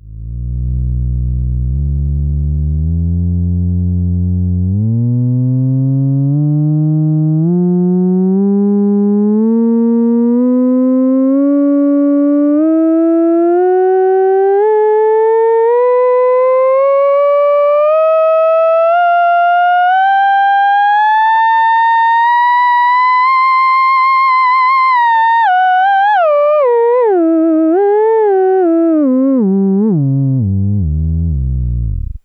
This sample is pure and true as I only normalized the level in Audacity. The volume control is fixed so the wave form can be viewed throughout the range.
The key is in attenuating the third harmonic, post processing would bring this to life with good hand movement.
In Audition the 3rd and 6th harmonics are down compared to the others for mid and higher frequencies.
ews-enhancer3.wav